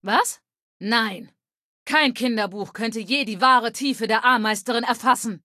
Datei:Femaleadult01default ms02 ms02antorigin 000b60cb.ogg